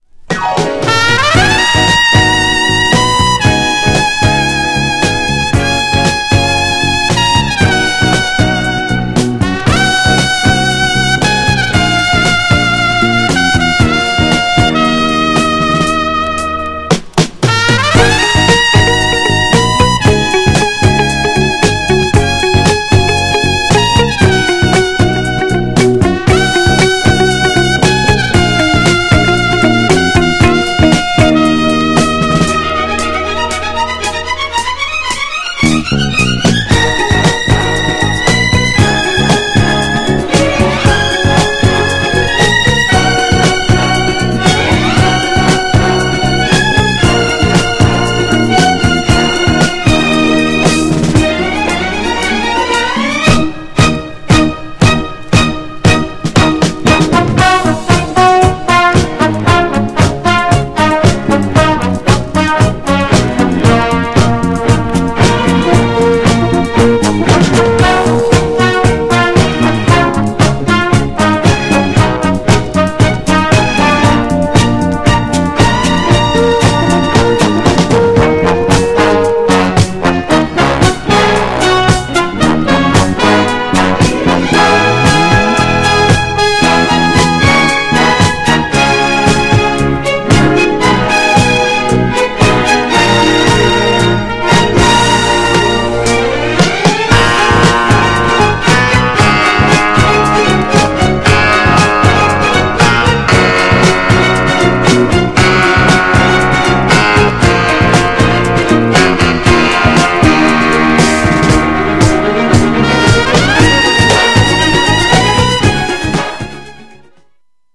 B面もクラシック!!
GENRE Dance Classic
BPM 111〜115BPM